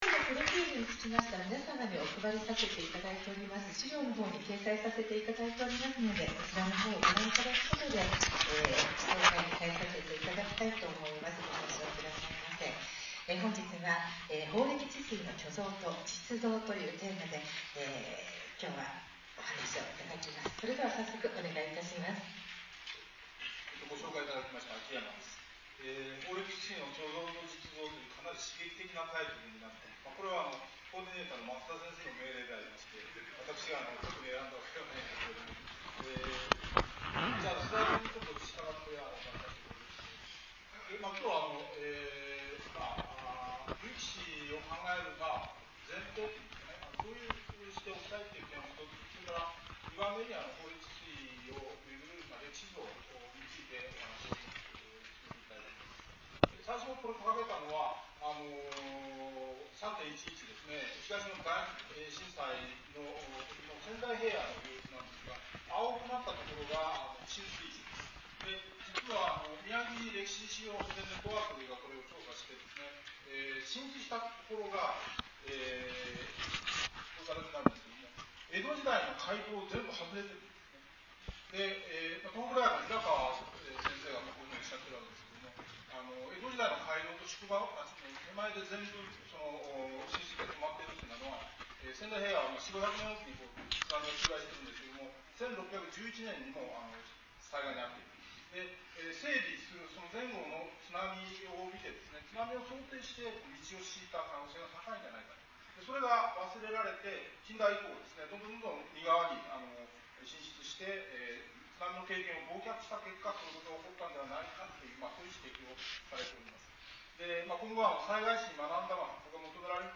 3月2日に この歴史事実を知る講演を聴くことができた。
場所は各務原市の漢方薬製造会社にある博物館である。3時間の講演では膨大な史料を精査し小説などにある創作を除外し事実だけ読み解く姿勢である。